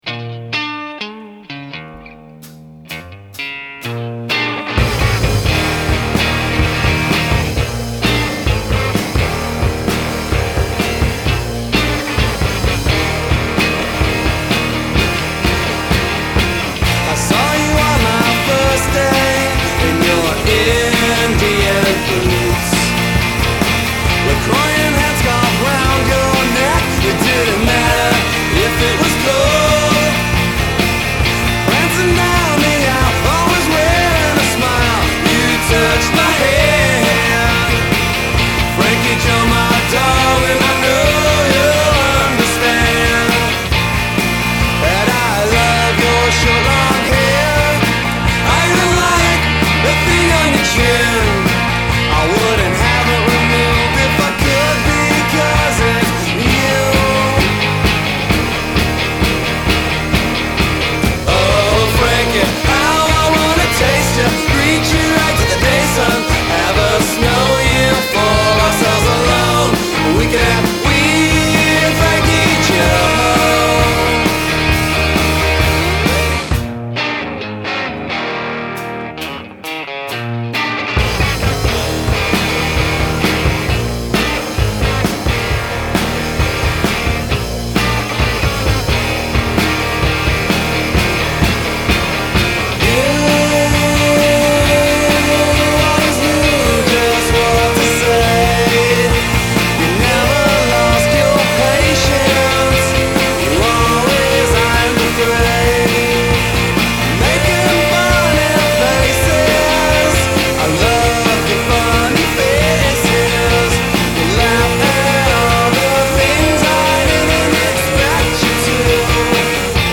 crunchy neo-alt-rock